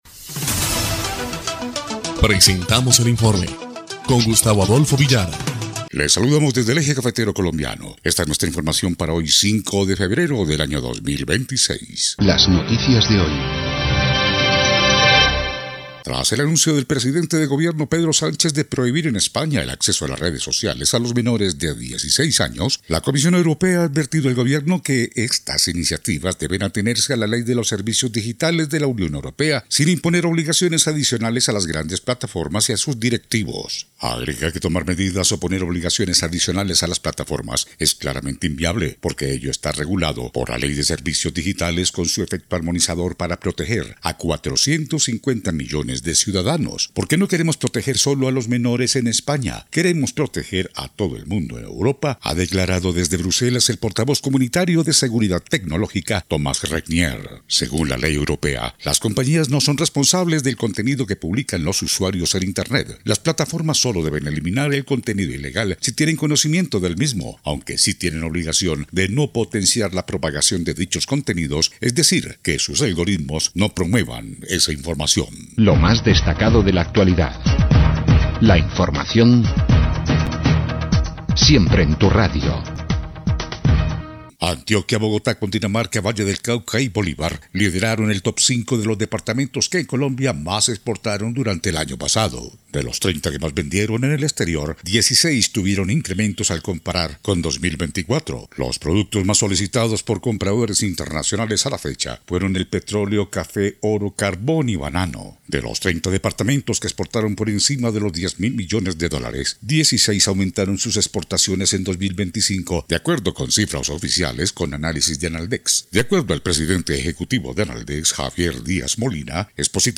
EL INFORME 2° Clip de Noticias del 5 de febrero de 2026